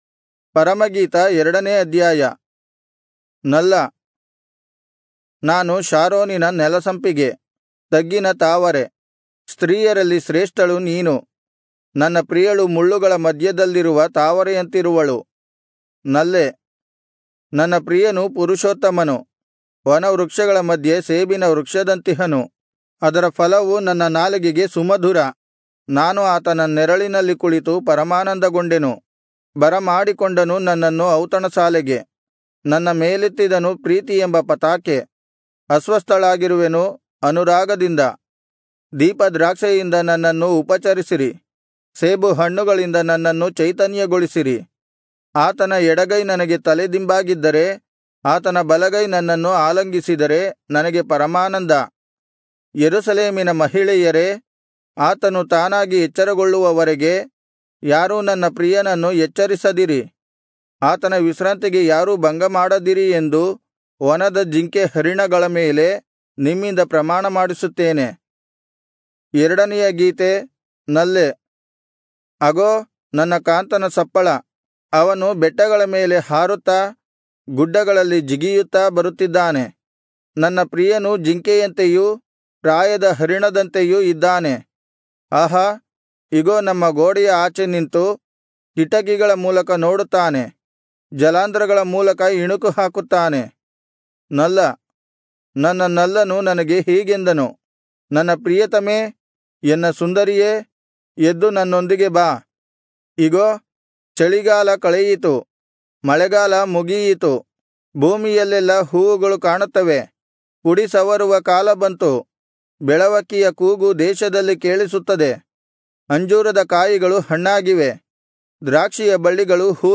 Kannada Audio Bible - Song-of-Solomon 4 in Irvkn bible version